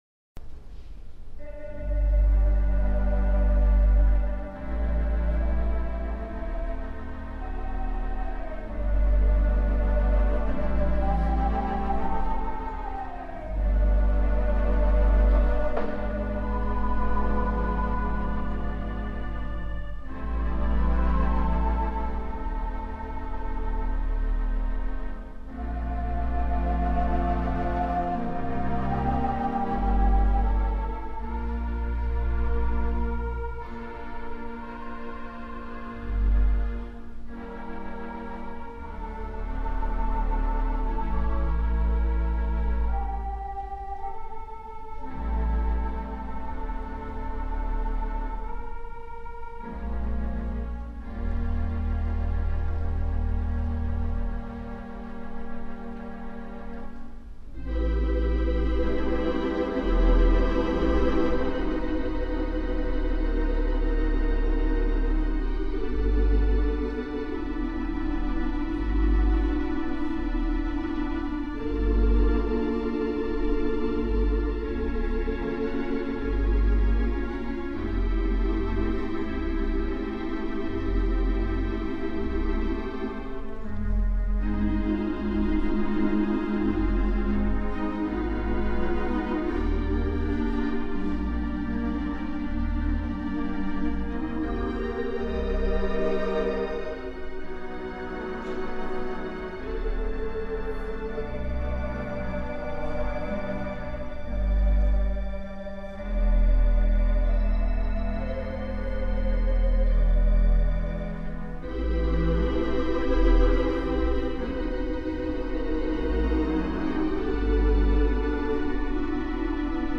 The Avalon Mighty WurliTzer